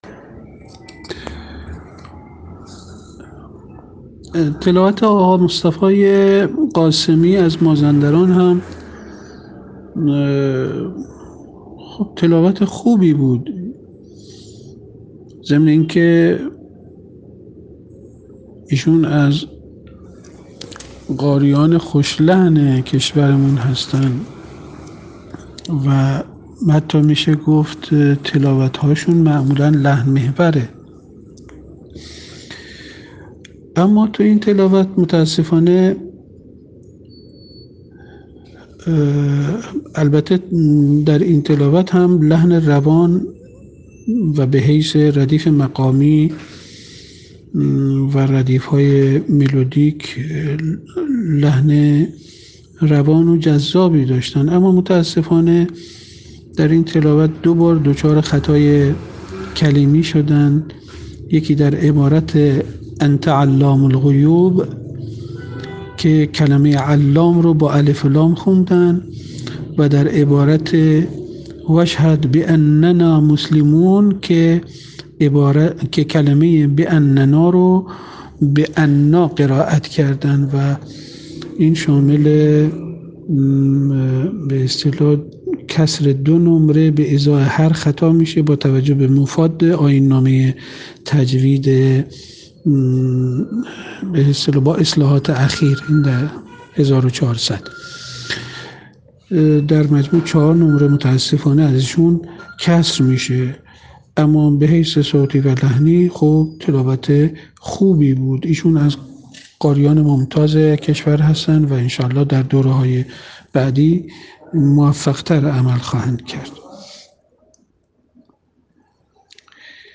در این تلاوت هم لحن از حیث ردیف مقامی و ردیف‌های ملودیک روان و جذاب بود اما متأسفانه در این تلاوت دو بار دچار خطای کلامی شدند.